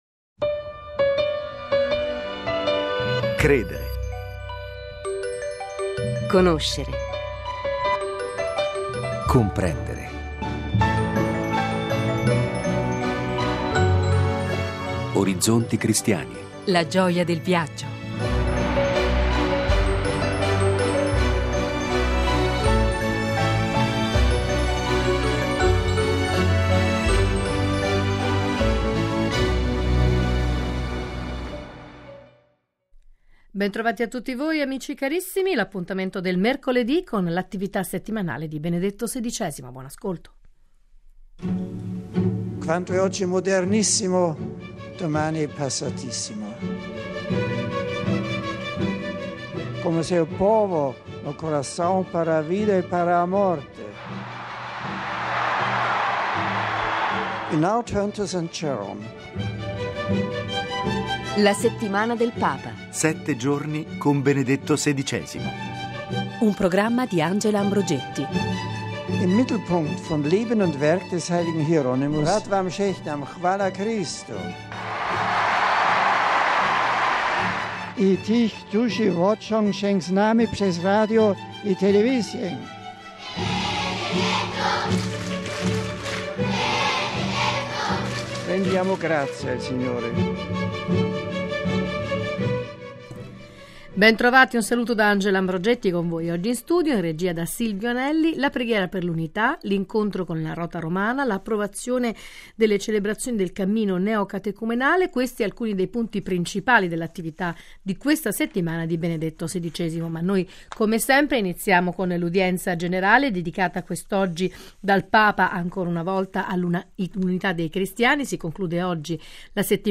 con la collaborazione di ospiti, ma soprattutto con l’ascolto diretto delle sue parole